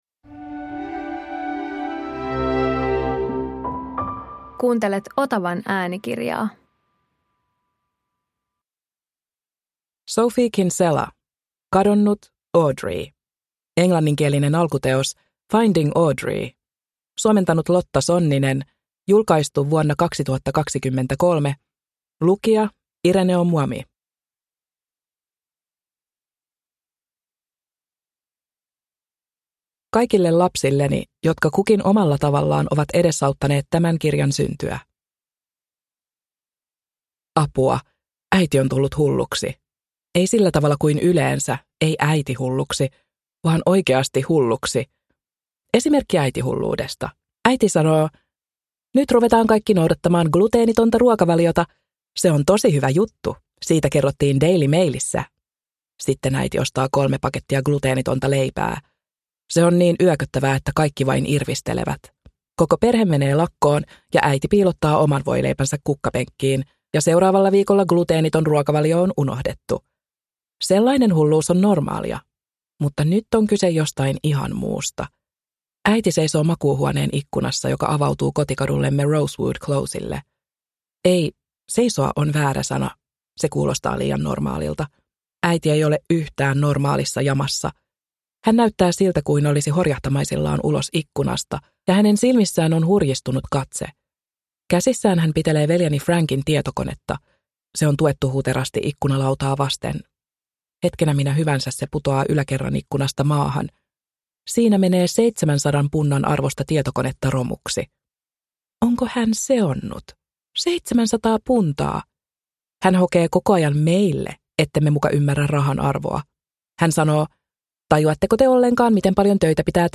Kadonnut: Audrey – Ljudbok – Laddas ner